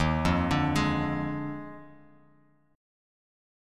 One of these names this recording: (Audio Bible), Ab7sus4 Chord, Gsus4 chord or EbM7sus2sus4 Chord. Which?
EbM7sus2sus4 Chord